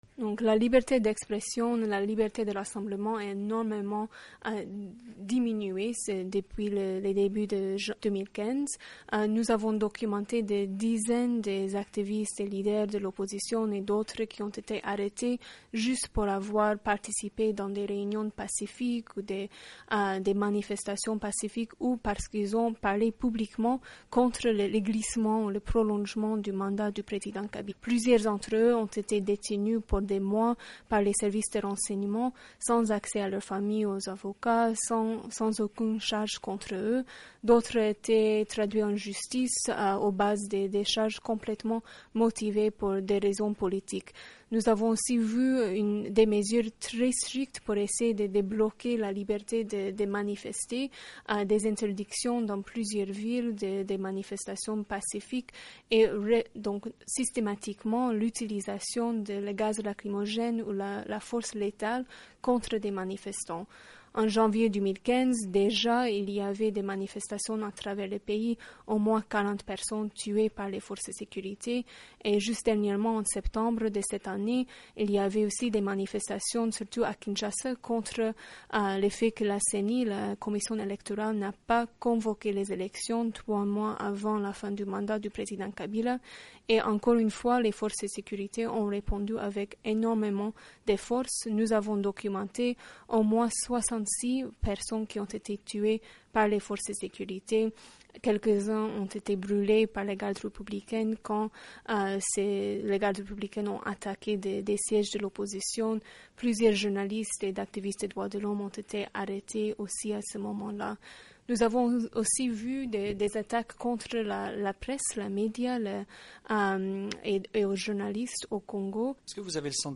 Le ton est mesuré mais les chiffres et les accusations contre les autorités congolaises frappent fort.